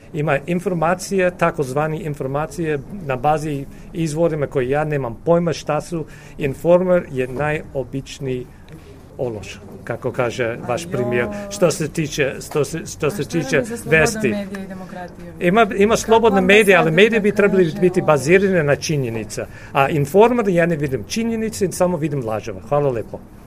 Ovako je Skat odgovorio na pitanje novinarke "Informera" o navodnoj blokadi evropskog puta Srbije zbog nezadovoljstva "načinom na koji Vučić vodi zemlju".